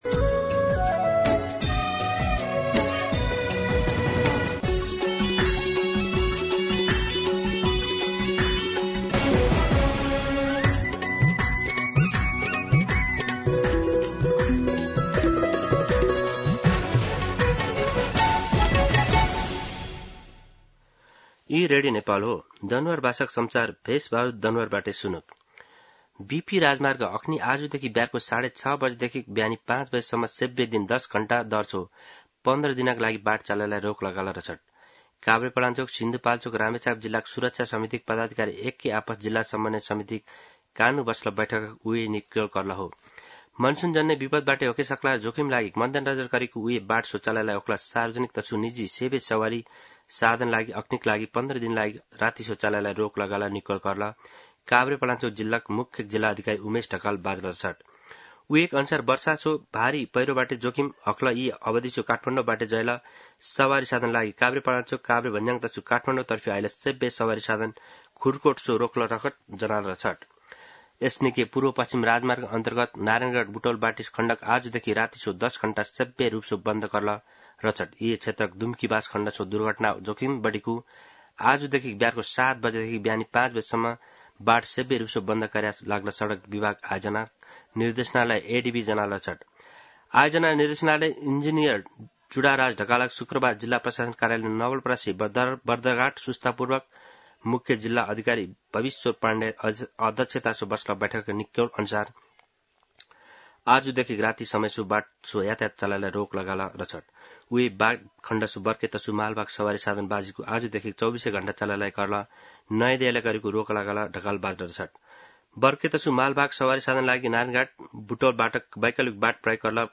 दनुवार भाषामा समाचार : ७ असार , २०८२